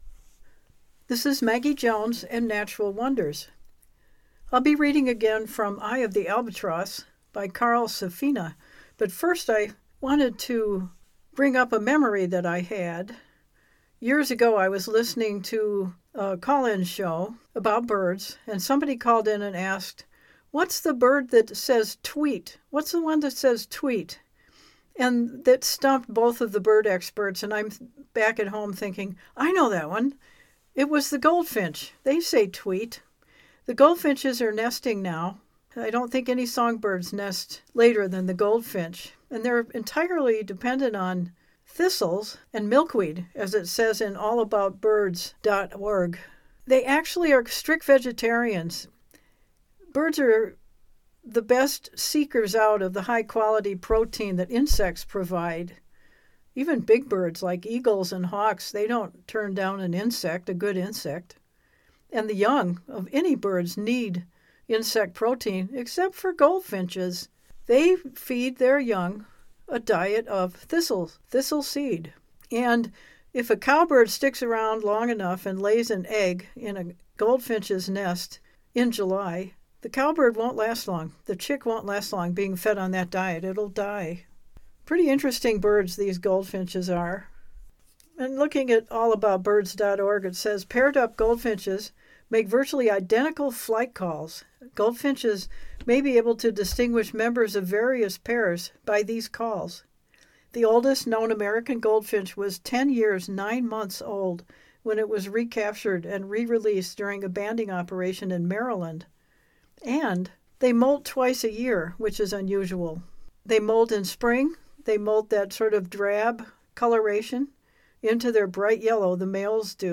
Another trip to the remote Pacific; Tern Island, part of the French Frigate Shoals, part of the western Hawaiian islands, where thousands of pelagic birds breed. I’m reading Eye of the Albatross by Carl Safina.